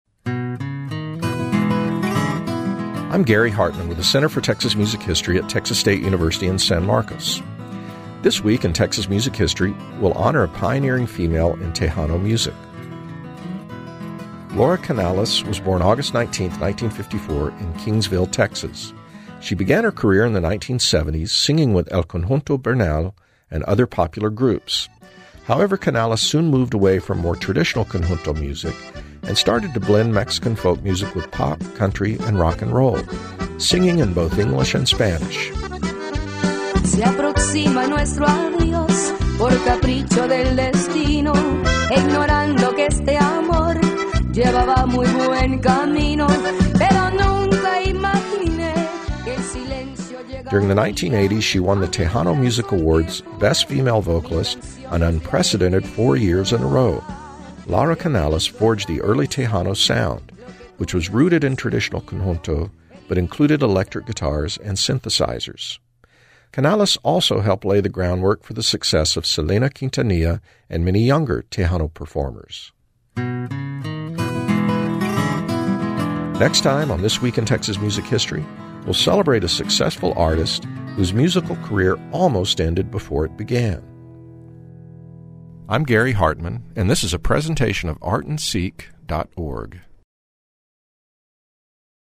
You can also hear This Week in Texas Music History on Friday on KXT and Saturday on KERA radio.